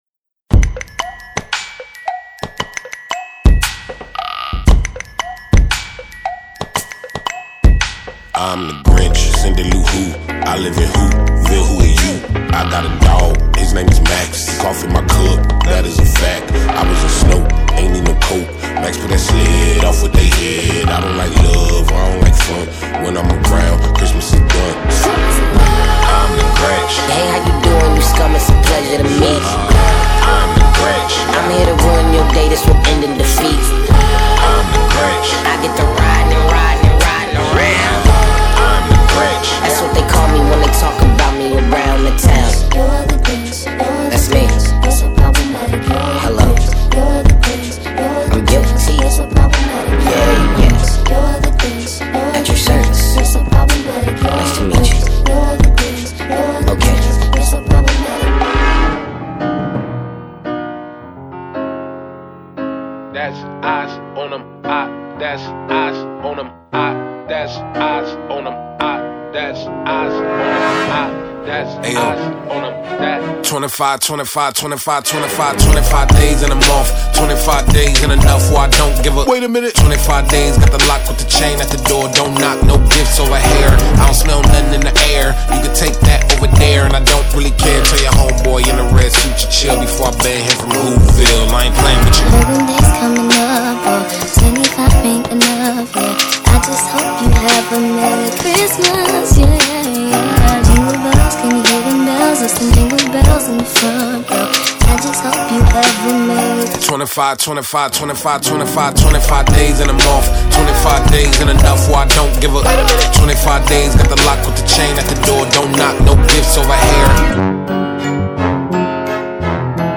Soundtrack, Holiday, Pop